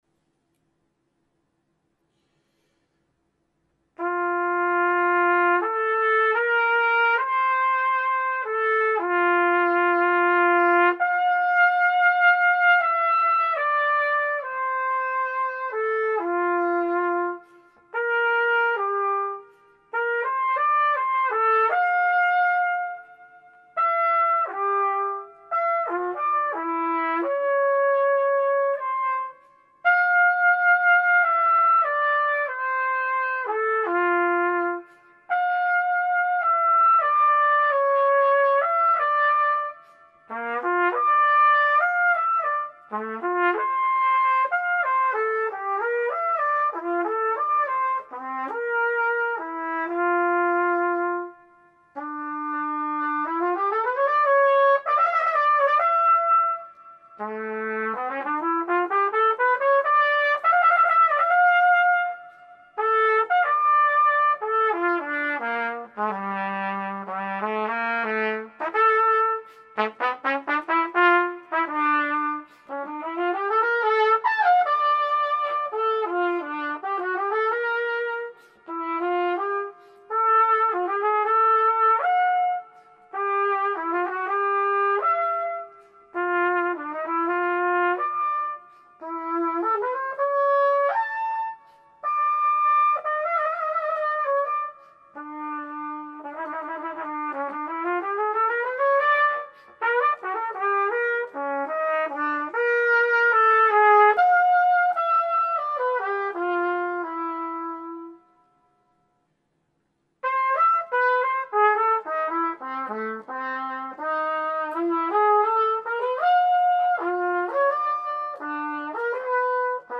Solo Trumpet